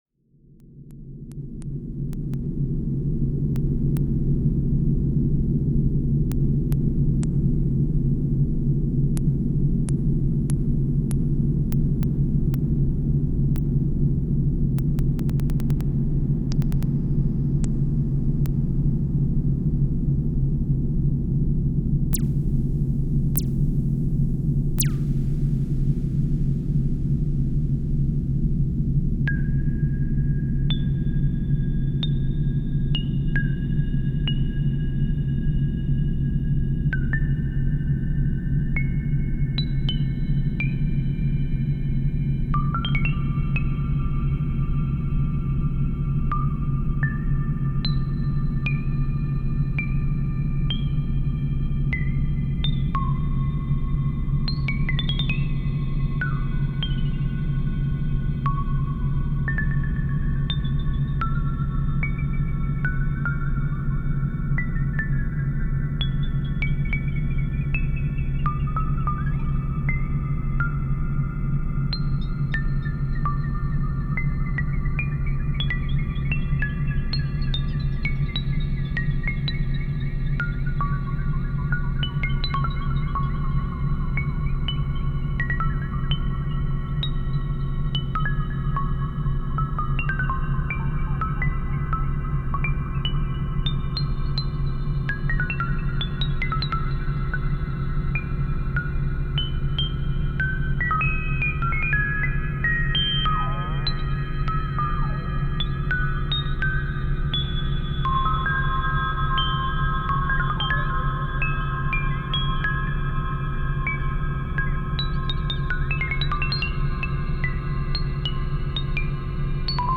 recipe for another nights of space pings:
if you put impulse as a machine you’ll get better pings though, add some lfo movement to the cutoff too